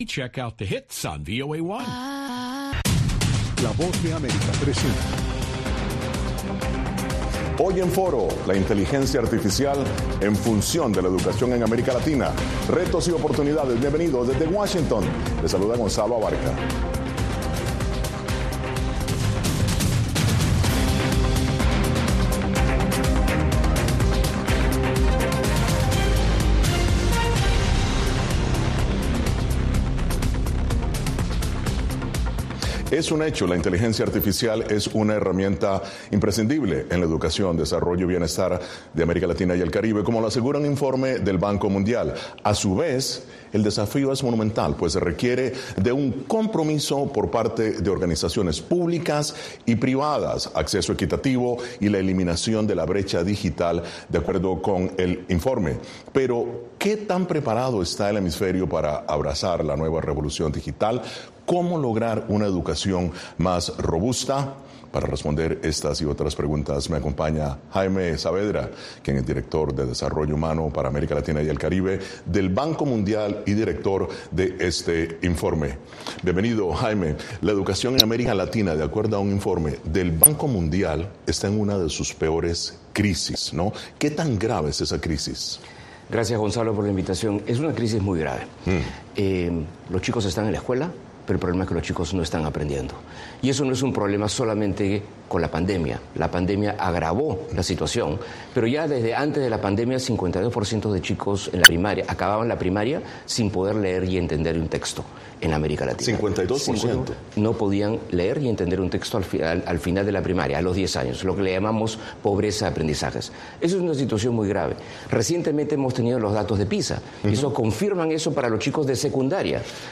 Foro (Radio): Educación: retos y oportunidades de la IA